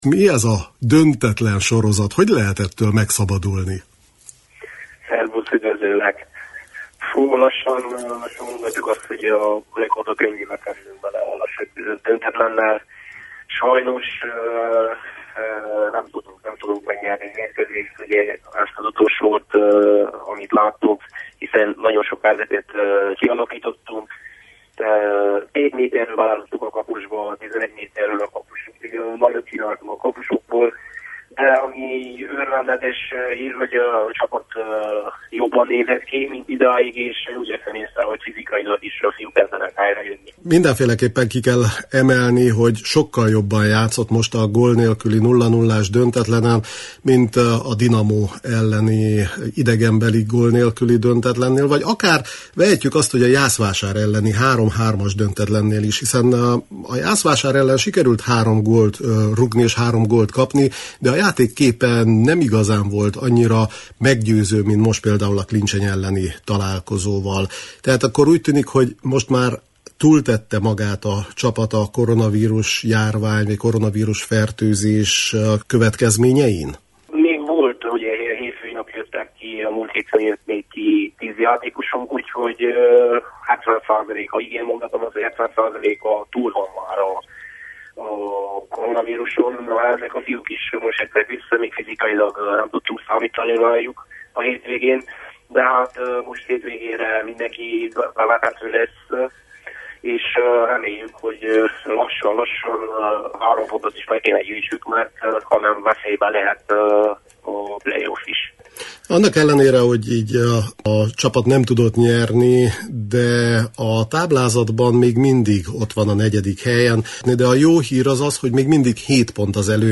(a választ az interjú után olvashatják…)